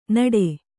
♪ naḍe